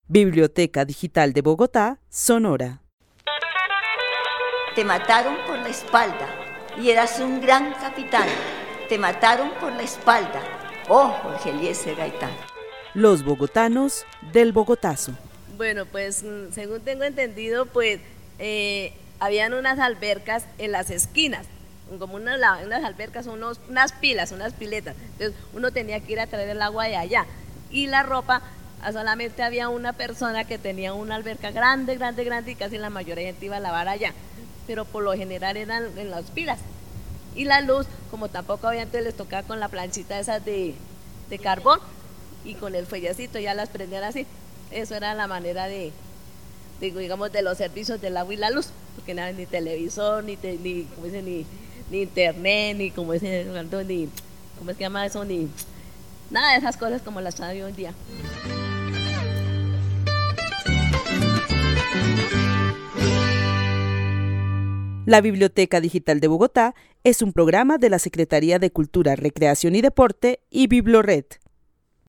Narración oral de los hechos sucedidos en Bogotá el 9 de abril de 1948.
También menciona cómo funcionaban los servicios públicos de electricidad y agua en esa época. El testimonio fue grabado en el marco de la actividad "Los bogotanos del Bogotazo" con el club de adultos mayores de la Biblioteca Carlos E. Restrepo.